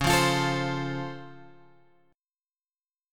C# Augmented Major 7th